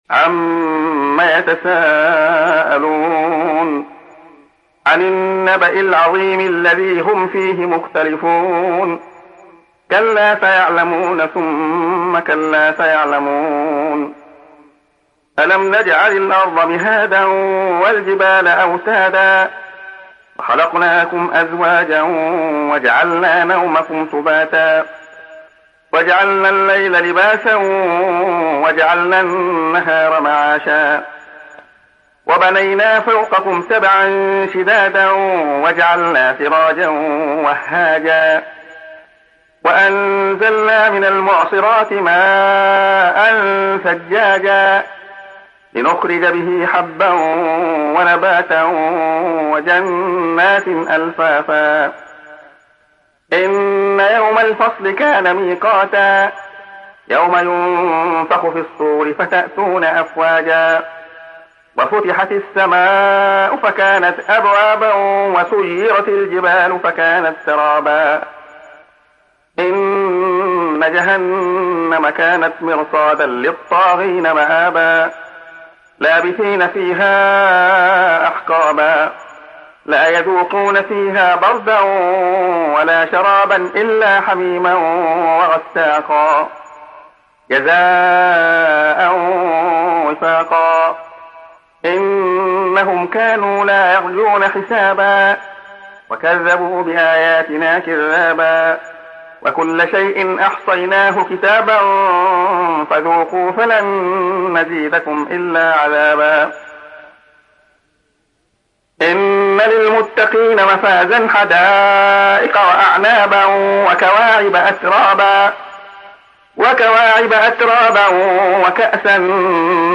Nebe Suresi İndir mp3 Abdullah Khayyat Riwayat Hafs an Asim, Kurani indirin ve mp3 tam doğrudan bağlantılar dinle